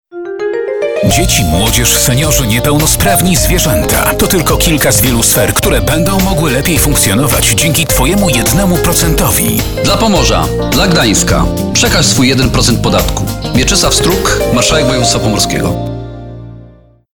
OPP-Marszalek-Gdansk-1.mp3